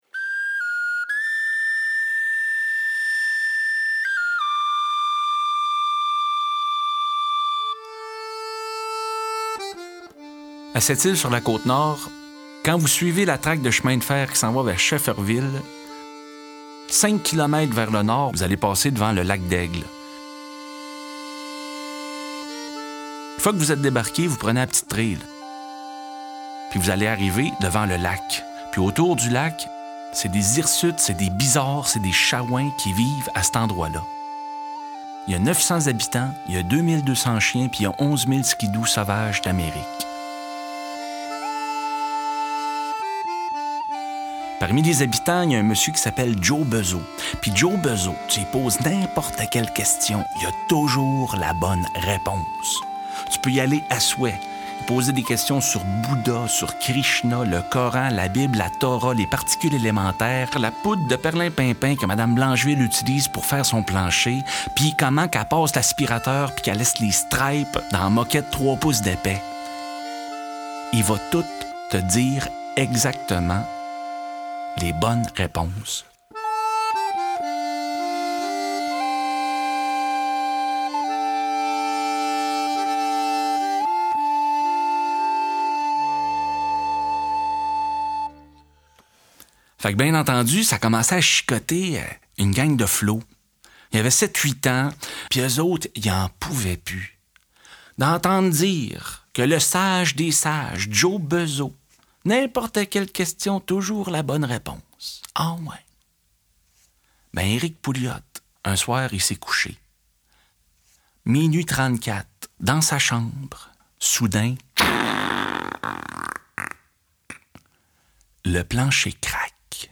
Diffusion distribution ebook et livre audio - Catalogue livres numériques
Là-bas, les princesses ne sont pas nées de la dernière averse, les trempeurs de couteau sont bons comme le pain, le diable vous invite à danser et Jos Bezeau connaît la réponse à toutes les questions… Défendu par la fi ne fleur des conteurs québécois actuels, cet album est le témoin passionné de l’éternelle jeunesse du conte dans la belle province.